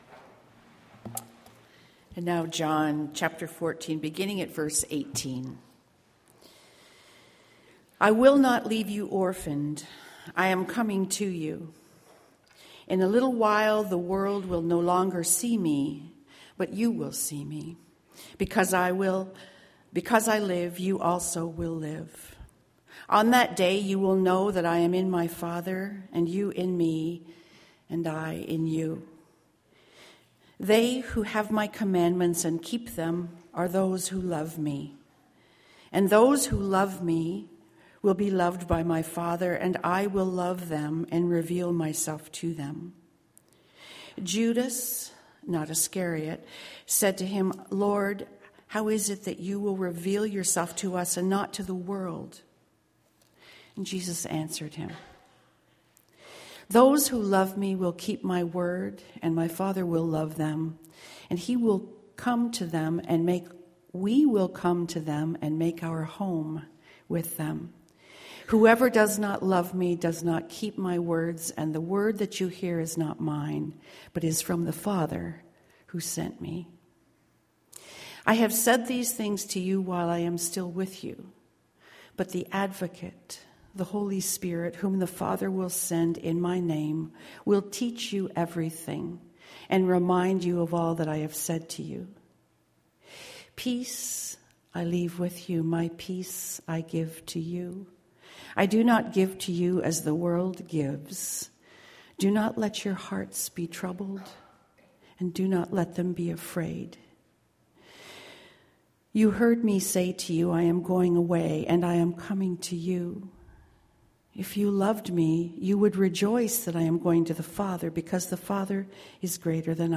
sermon_mar20.mp3